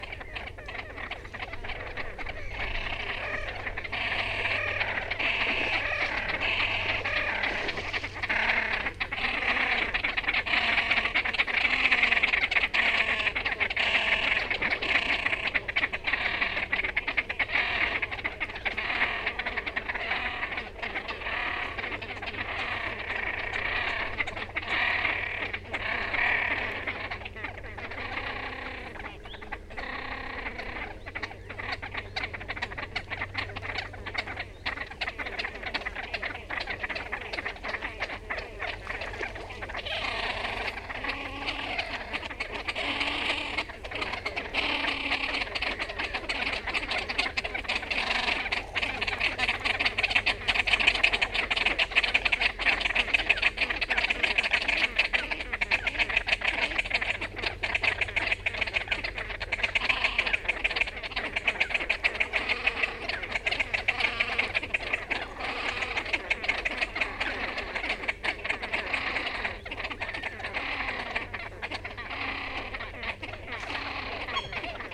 Mergullón cristado
Podiceps cristatus
Canto